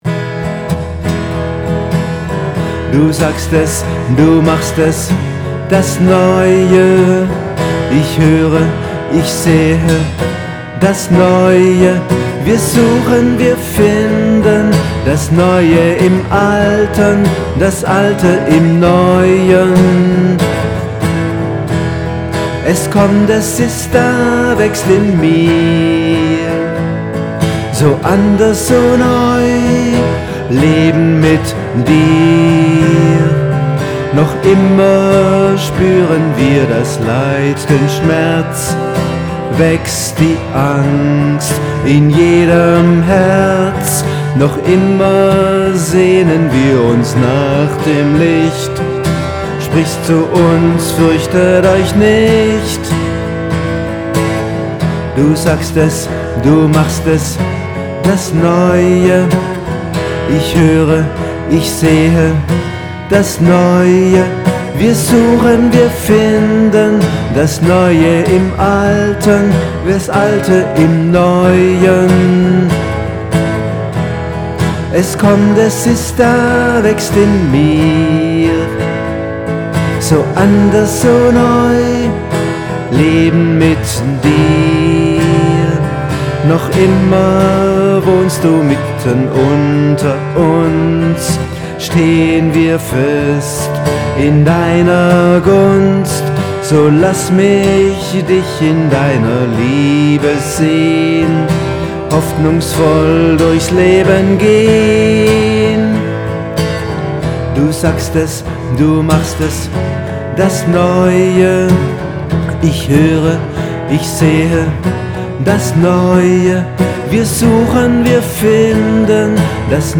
Hinweis für Gitarre: Es gibt zwei Versionen.